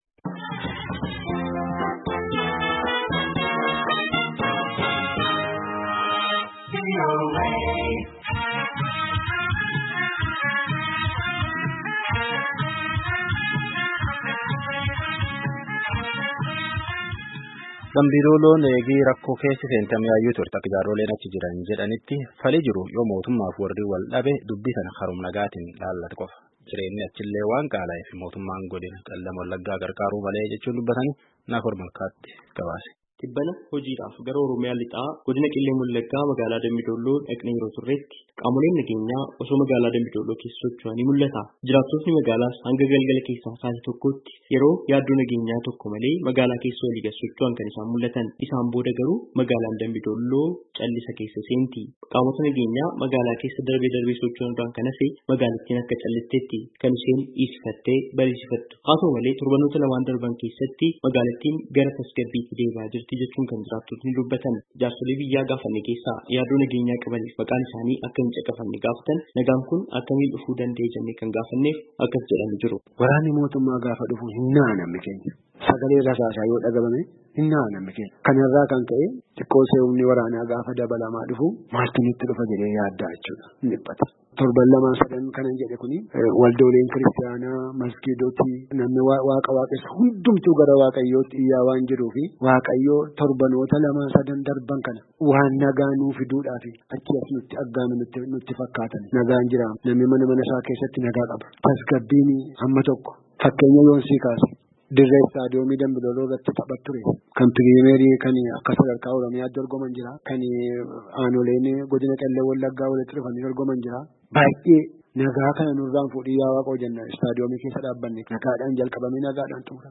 DAMBI-DOOLLOO, OROMIYAA —
yaada maanguddoota fi ka gama mootummaas Bulchaa Godinaa Qellem Wallaggaa irraa gaafatee gabaasaa qindeesse.